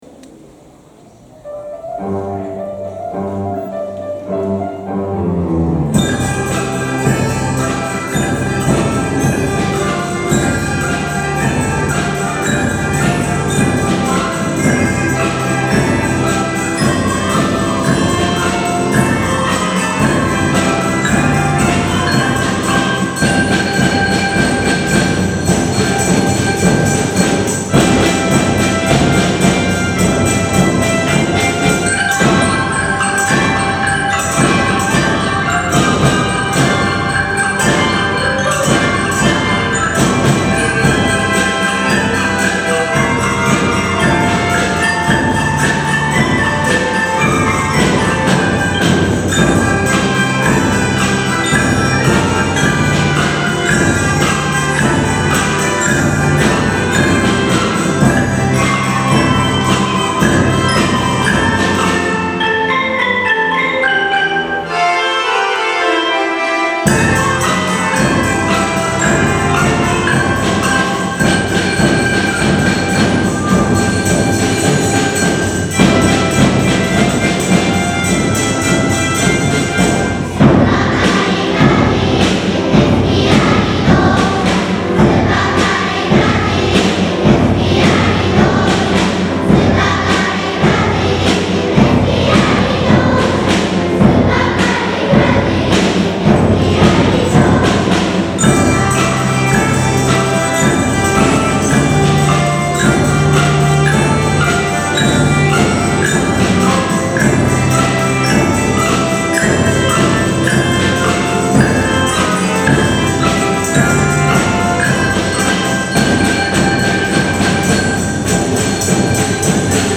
３年生は、１学期よりもさらにバージョンアップしたリコーダーで合奏をつくりました。
曲調に合わせて強弱をつけることにもチャレンジしました。
曲の途中で、ピアノ・小だいこ・大だいこ以外の全員が「スーパーカリフラ〜」の歌詞を歌い、３年生はダンスもしました。